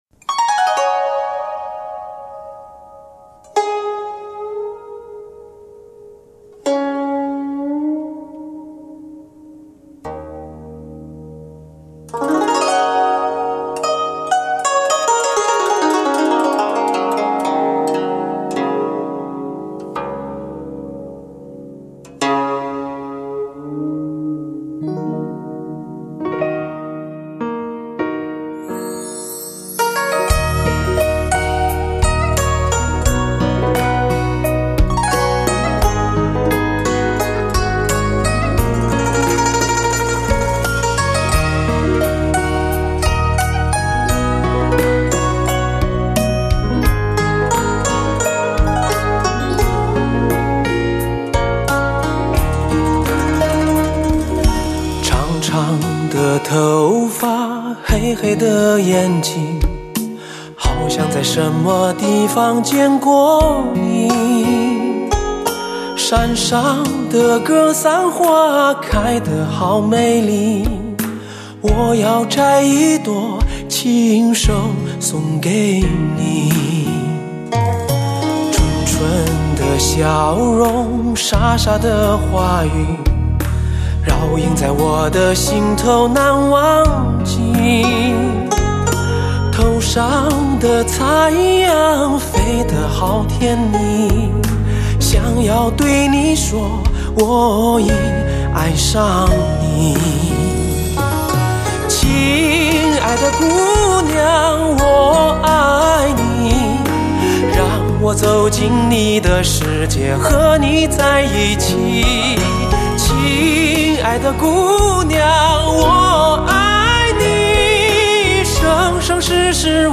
史上最具HI-FI震撼的发烧男声
技惊四座的演唱，震撼全场的音效，吸引所有目光的美妙靓声